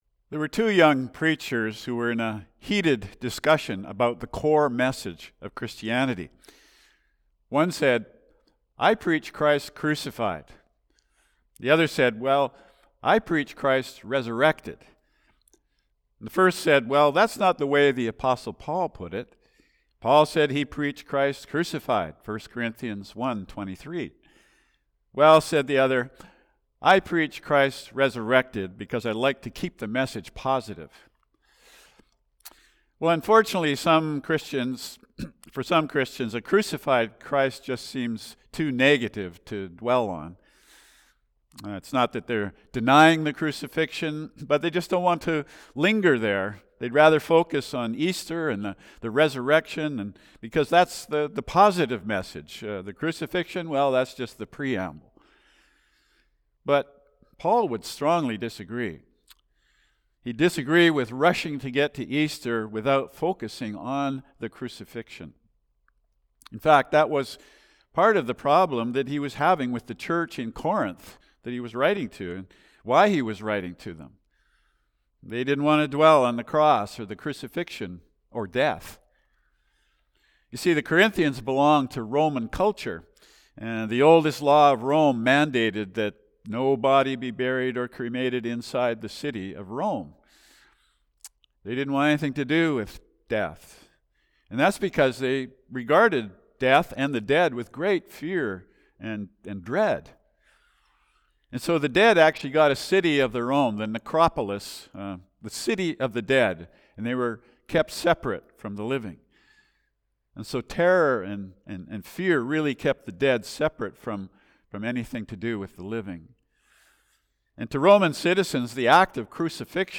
A Good Friday Message – Calvary Baptist Church of Gibsons